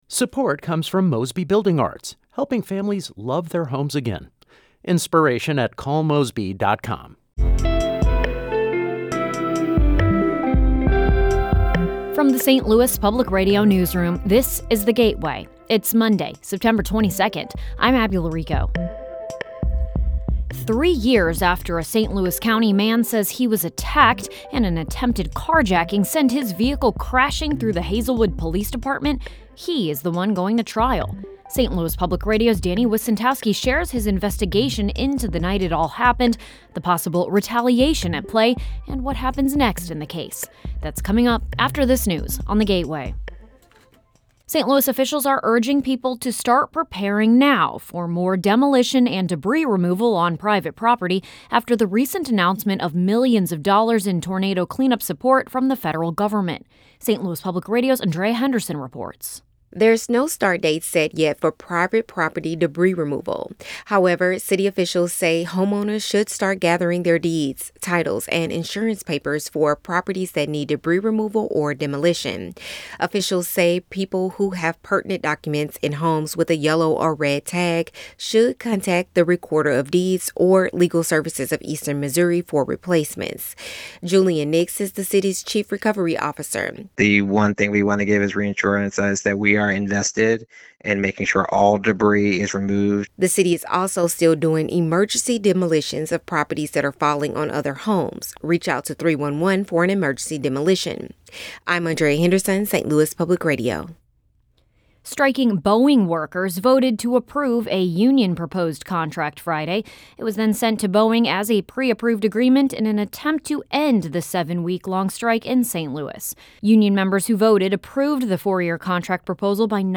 Essential news for the St. Louis region. Every weekday, in about 8 to 10 minutes, you can learn about the top stories of the day, while also hearing longer stories that bring context and humanity to the issues and ideas that affect life in the region.